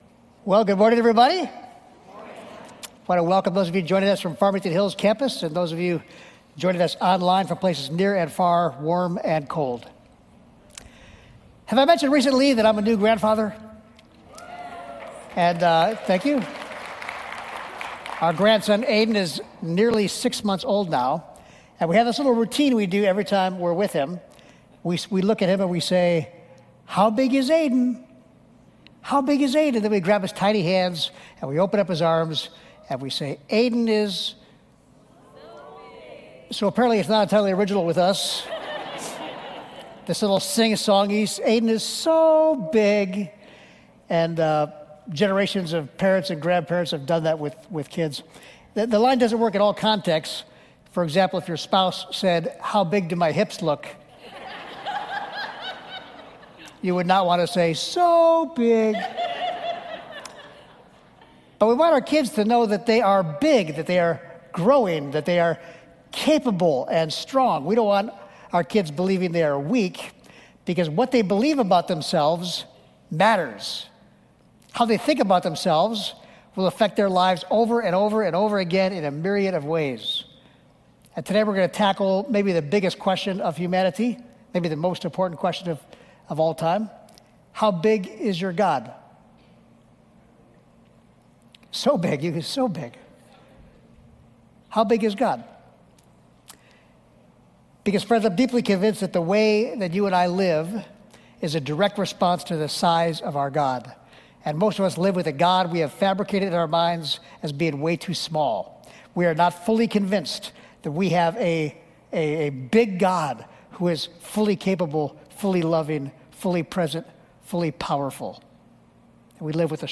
Podcast of Sermons from Ward Church in Northville, Michigan.